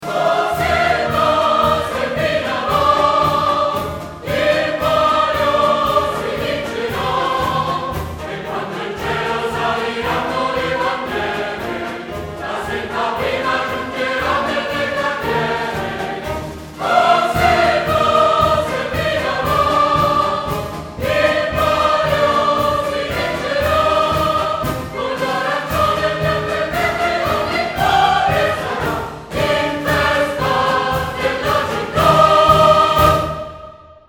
suoneria per smartphone